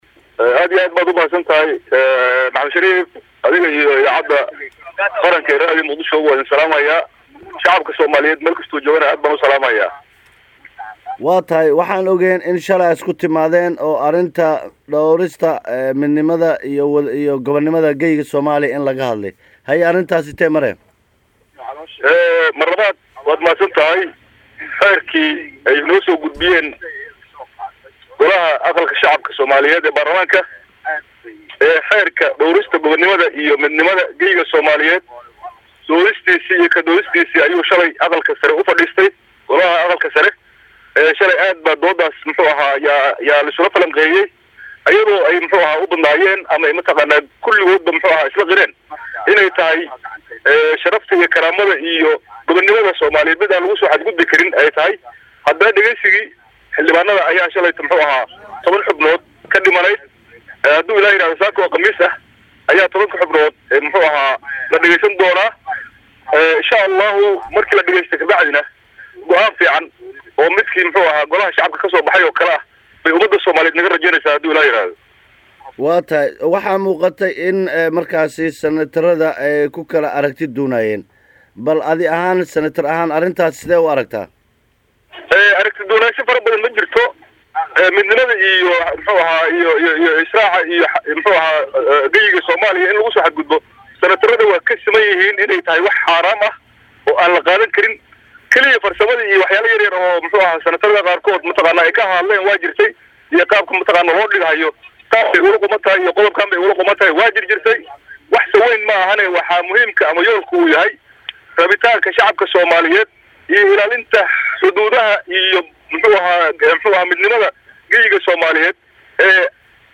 Xildhibaan C/llaahi Sheekh Ismaaciil oo ka mid ah xubnaha Aqalka Sare oo la hadlay Radio Muqdisho ayaa sheegay xildhibaanada haray markii ay dhiibtaan aragtiyadooda in cod loo qaadi doono xeerkaan ka soo gudbay Golaha Shacabka.
WAREYSI-XILDHIBAAN-AQAALKA-SARE-FARTAAG-.mp3